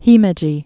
(hēmĕ-jē, hē-mĕjē)